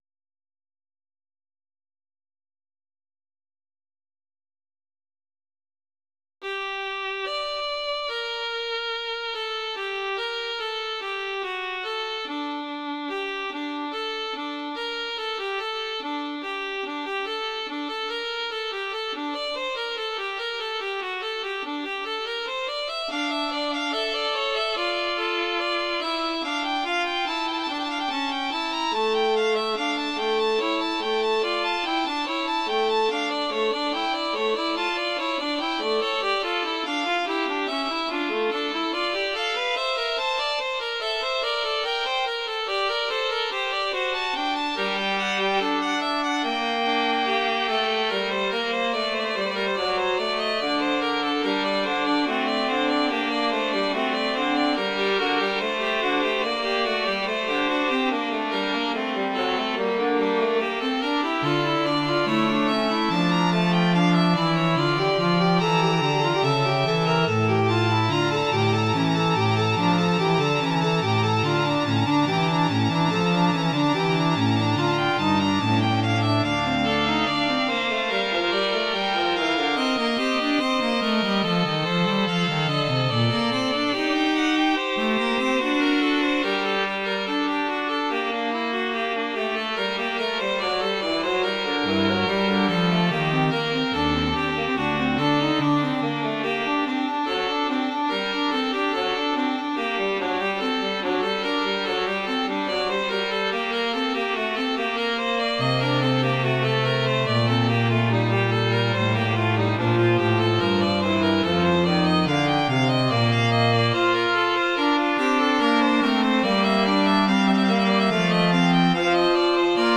音源は全てVSC-88です。